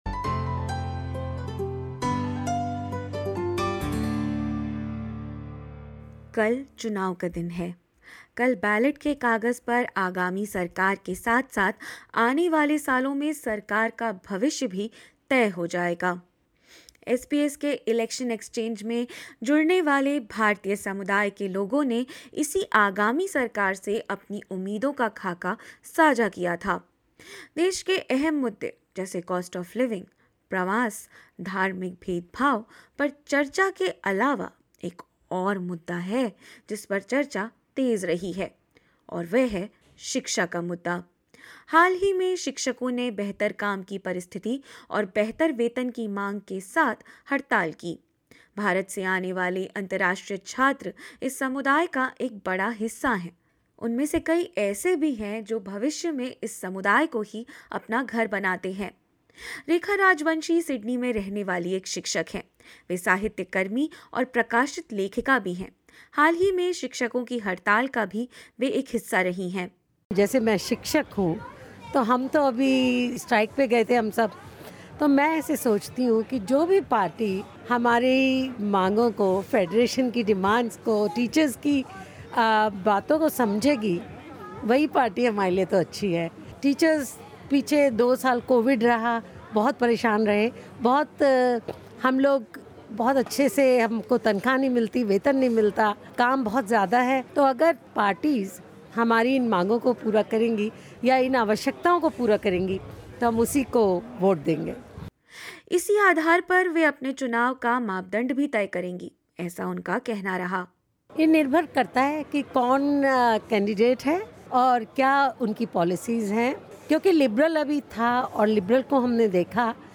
In this fourth episode of the five-part 'Humara Neta Kaisa Ho' series, various candidates from different parties share their viewpoints on the education and challenges faced by international students in Australia. SBS Hindi discusses those vital issues at the Election Exchange which was recently held in Sydney.